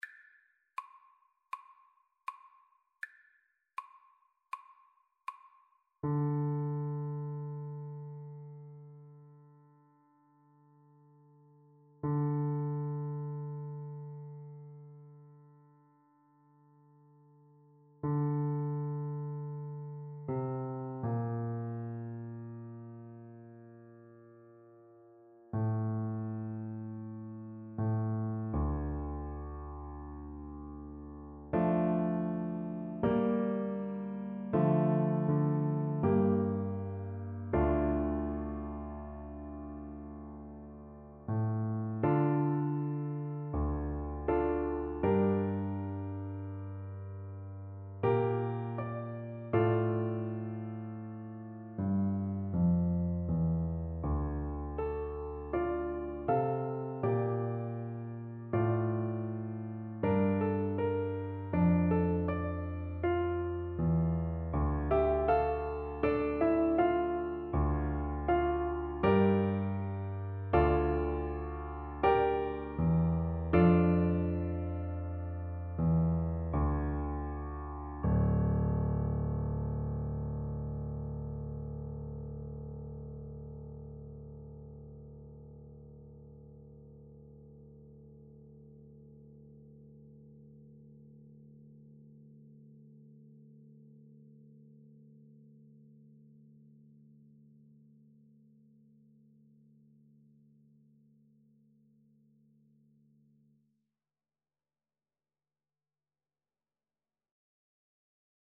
Traditional Music of unknown author.
4/4 (View more 4/4 Music)
Andante espressivo